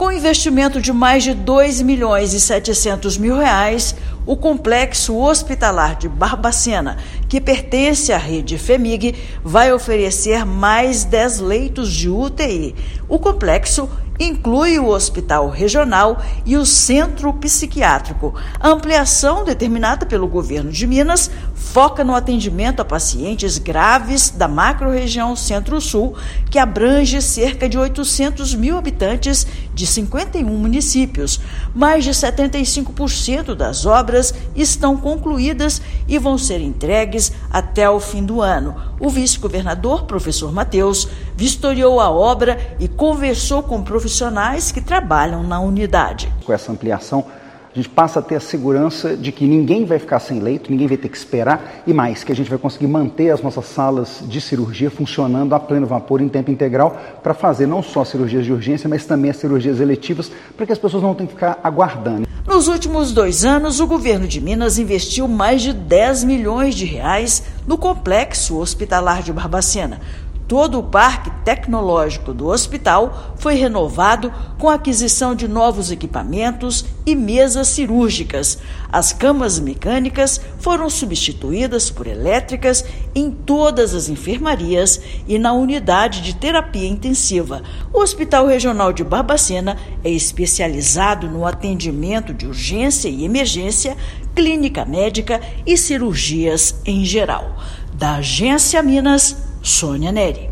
Complexo Hospitalar do estado é referência no atendimento para 800 mil habitantes da macrorregião. Ouça matéria de rádio.